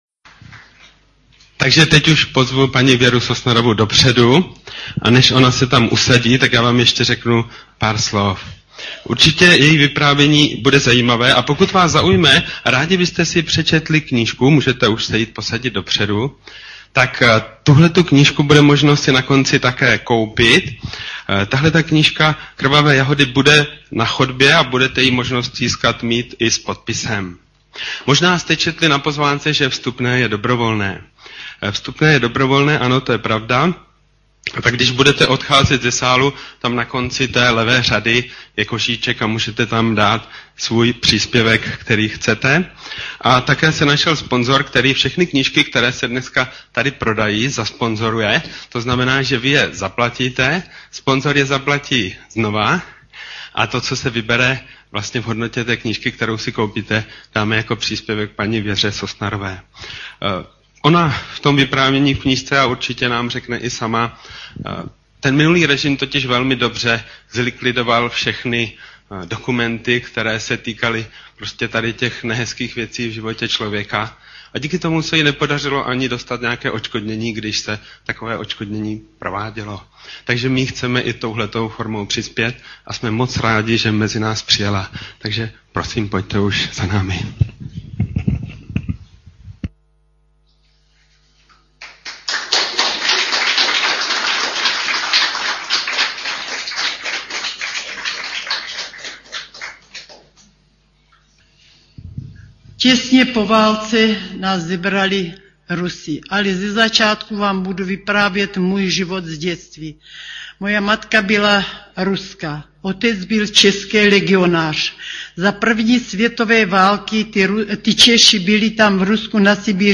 Záznam z jejího znojemského vystoupení si můžete poslechnout zde. http